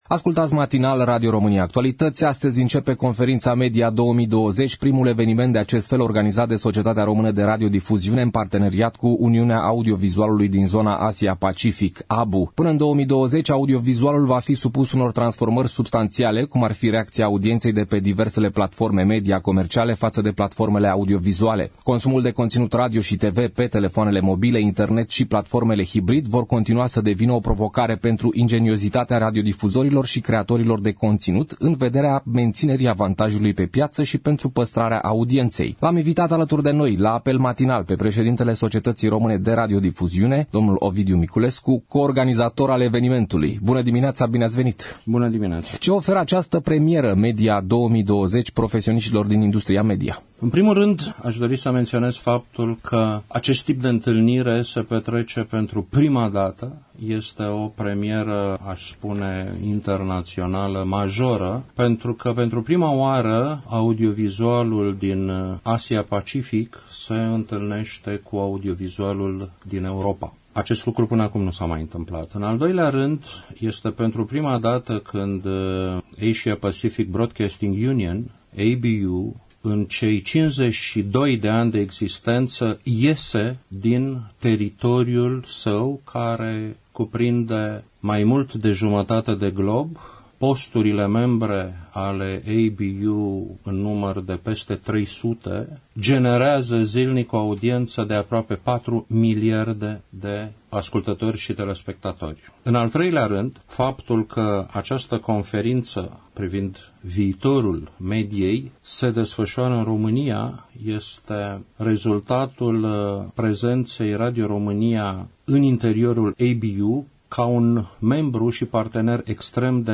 Audio: Interviu cu preşedintele director general al SRR, Ovidiu Miculescu.
interviu-Ovidiu-Miculescu.mp3